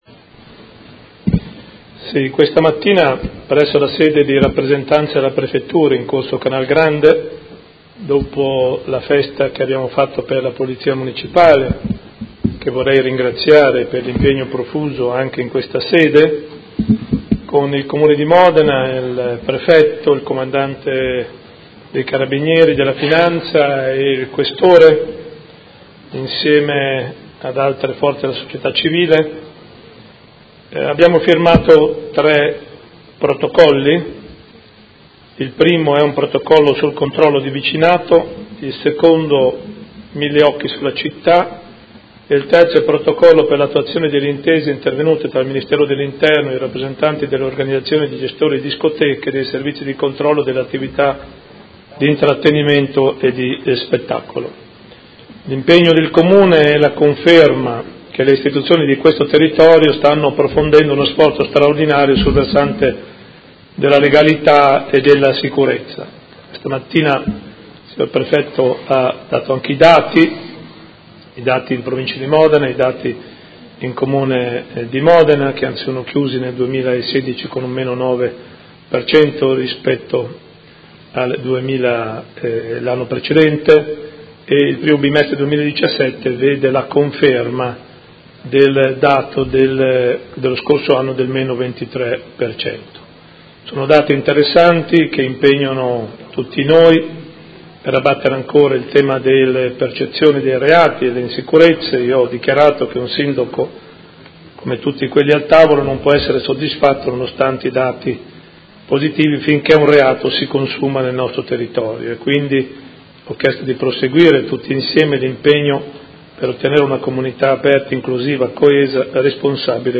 Seduta del 25/05/2017 Comunicazione su sicurezza.